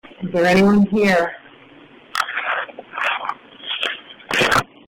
EVP's
in the kitchen area